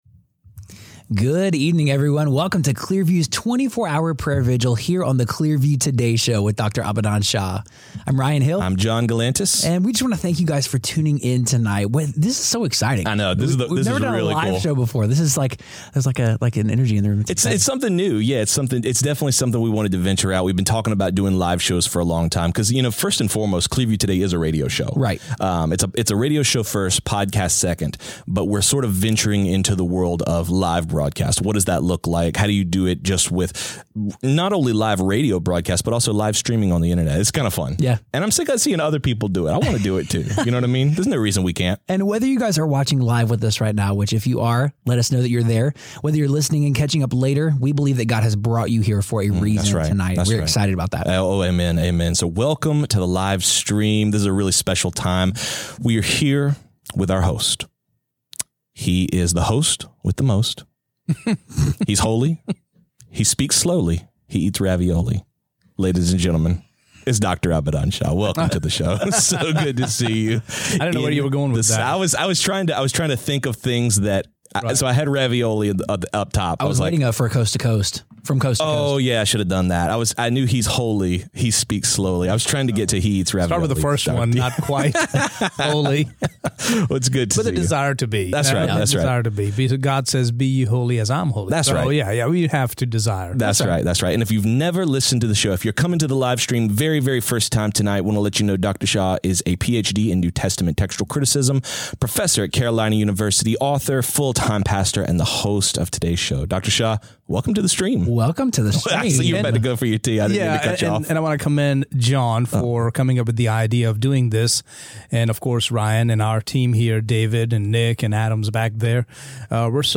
BONUS: 2-Hour Prayer Livestream